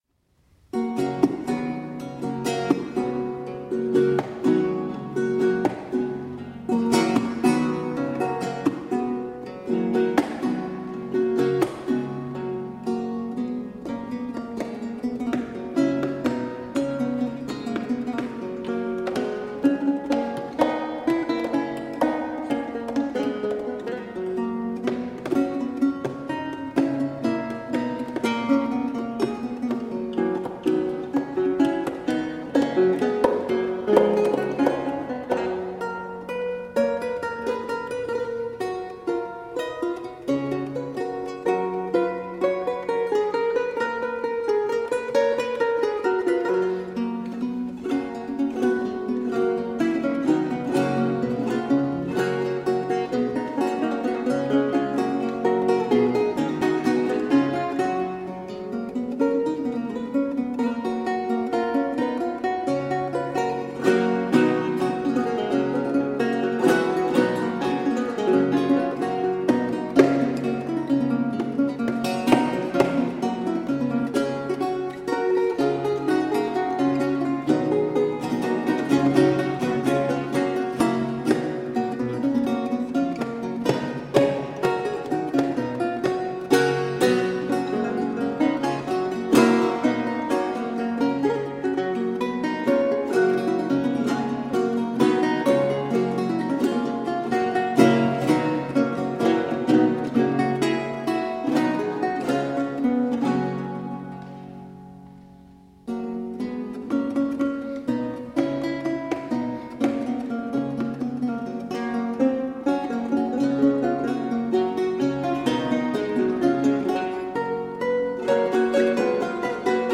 Chilean baroque guitar duo.